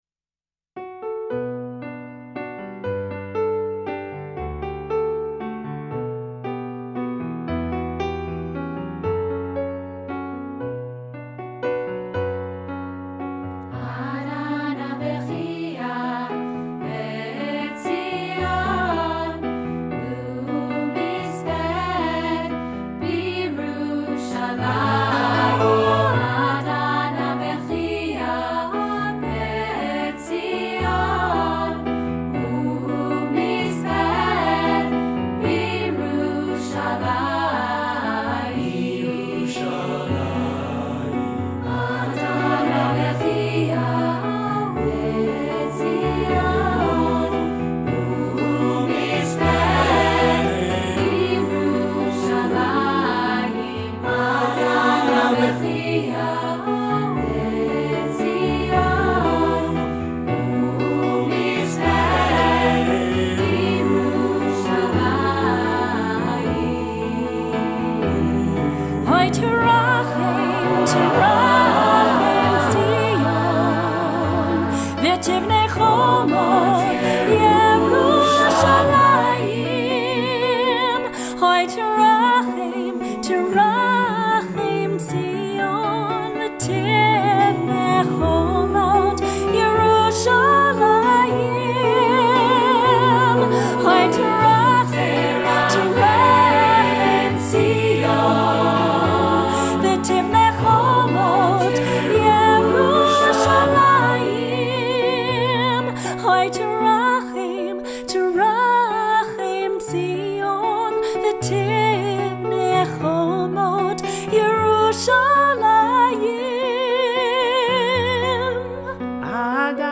Genre: Religious
Contains solos: Yes